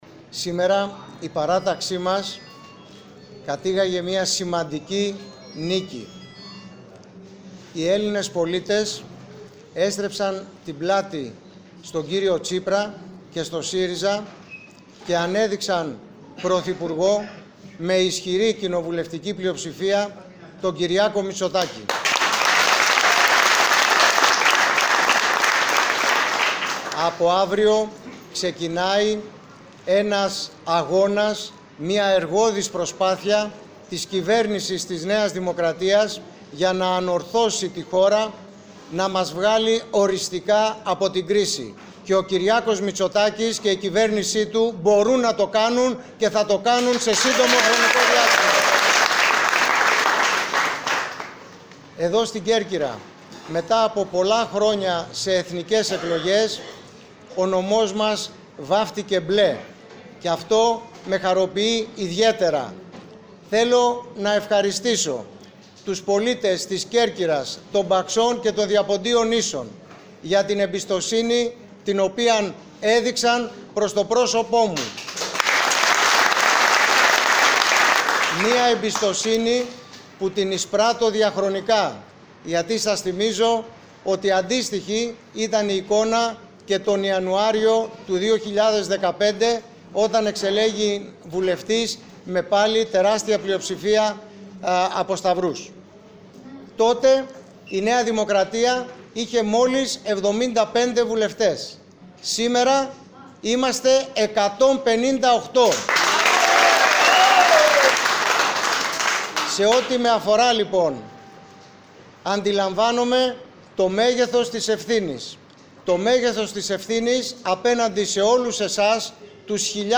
Ο κ. Στέφανος Γκίκας μίλησε για προσωπική δικαίωση, ευχαρίστησε τον οικογενειακό του κύκλο και τους συνεργάτες του ενώ τόνισε ότι θα εργαστεί εκ μέρους του συνόλου των κερκυραίων για την προώθηση της επίλυσης  προβλημάτων που αντιμετωπίζει το νησί μας.